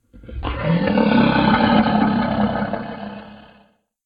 beast_roar_dinosaur.ogg